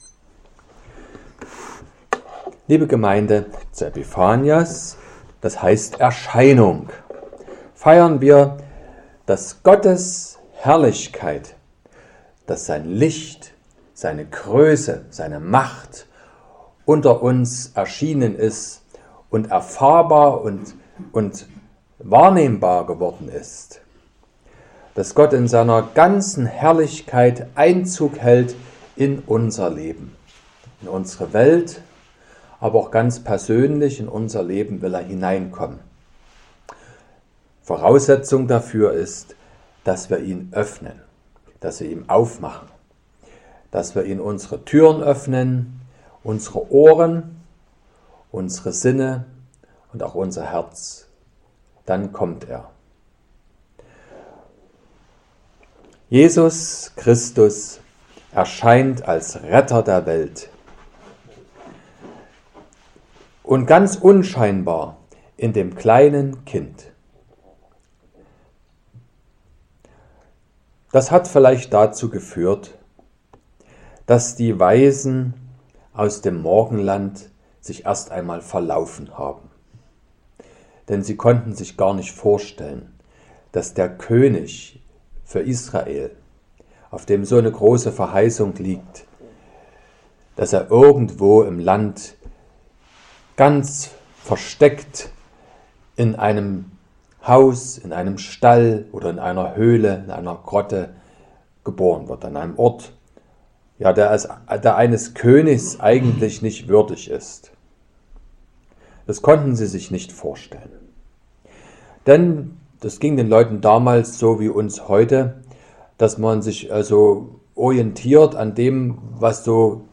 05.01.2025 – Epiphaniasgottesdienst
Predigt und Aufzeichnungen